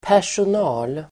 Uttal: [pär_son'a:l]